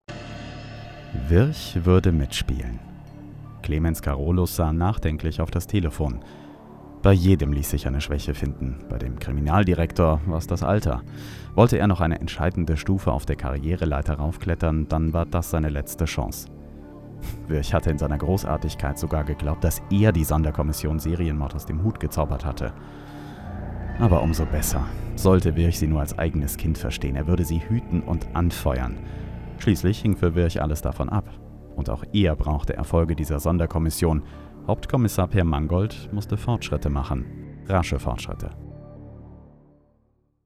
Sprechprobe: eLearning (Muttersprache):
Professional Speaker for News, OFF, E-Learning, Industrial and more...